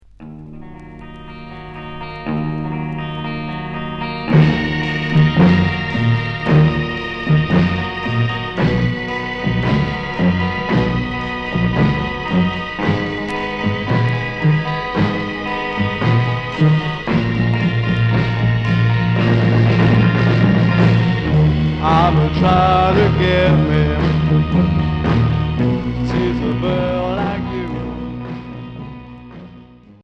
Progressif Unique 45t